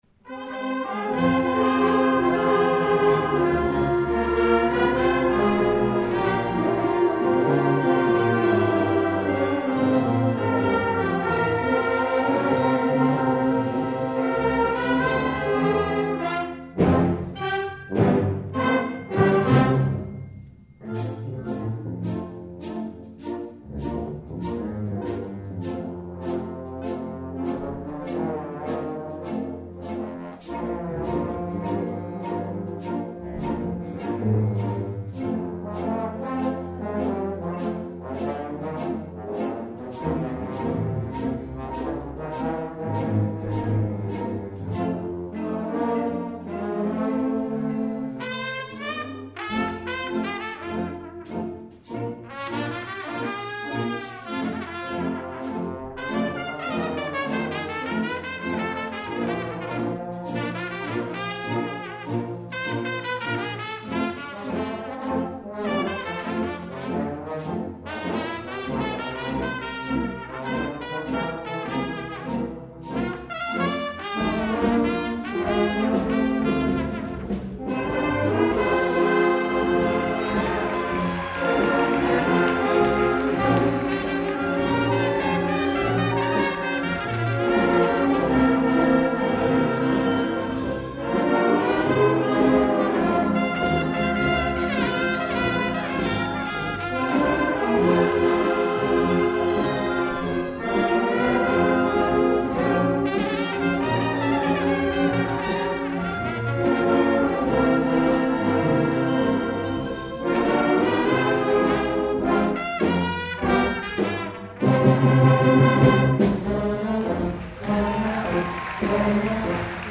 2009 Winter Concert
FLUTE
CLARINET
TRUMPET
PERCUSSION